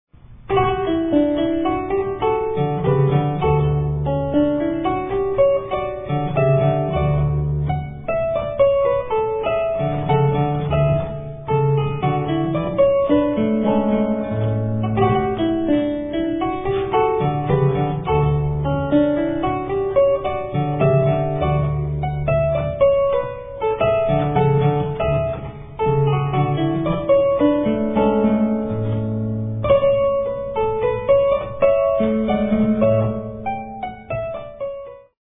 About the exclusive use of the Peau de Buffle stop
It's also very relaxing listening."
gut-strung lautenwerck (lute-harpsichord)
The delicate, intimate tone of the lautenwerck